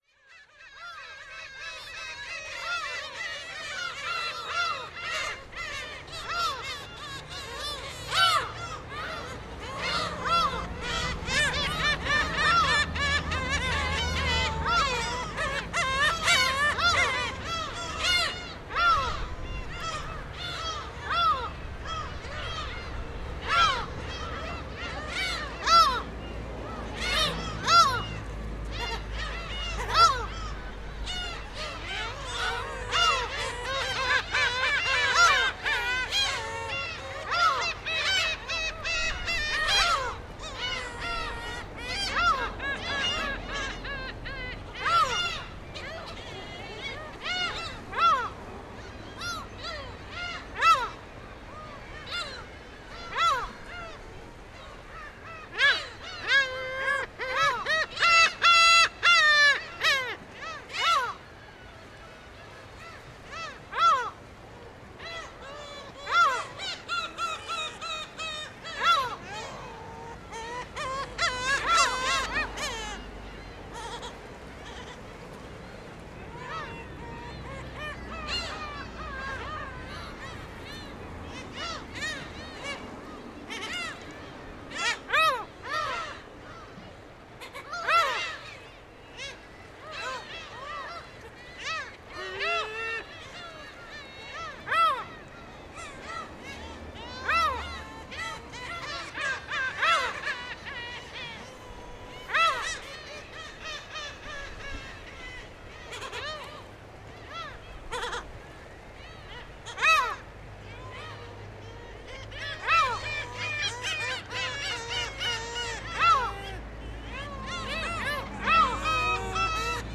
ウミネコの営巣地
ZOOM H6, RODE NT5 ORTF STEREO2015年3月17日 鶴岡市
予想通り、もう多くが集結していた。産卵は４月後半からだが、すっかり賑やかだ。